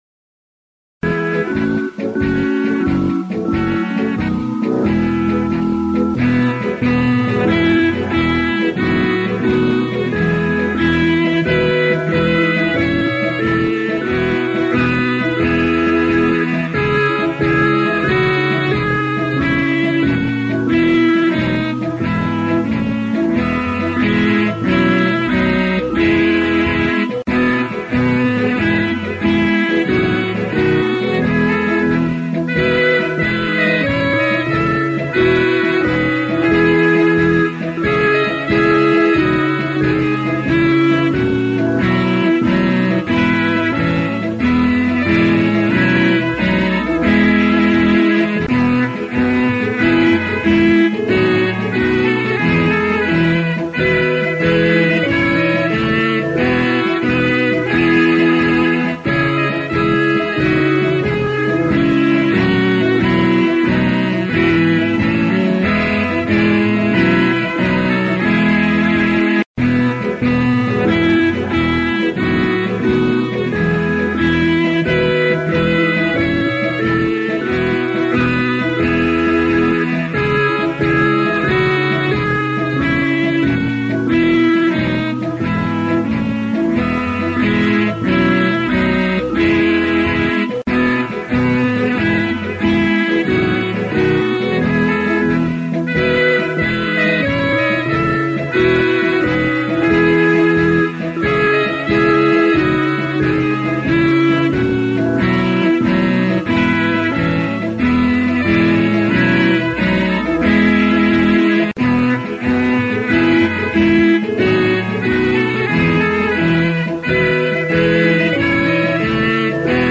[This accompaniment includes a prelude]